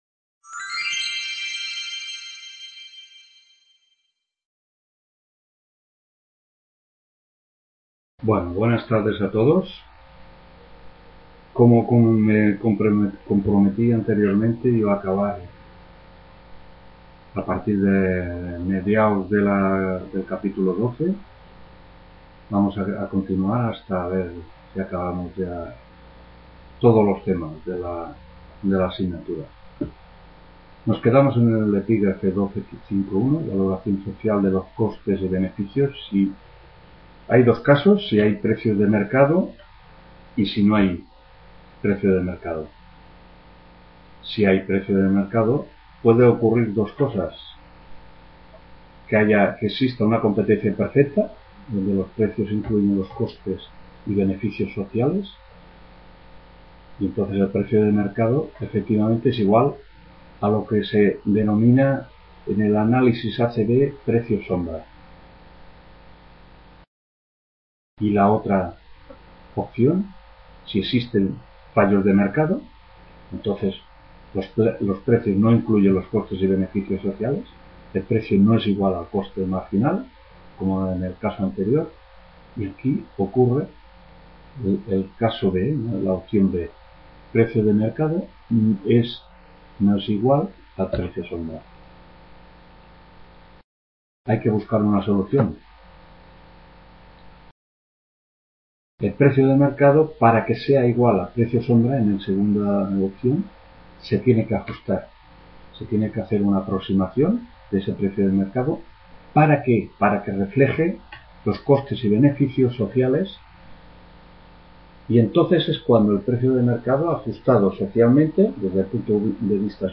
5ª TUTORÍA 17 ENERO 2012 (C) TEORIA DEL PRESUPUESTO Y…